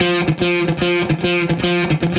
AM_HeroGuitar_110-F01.wav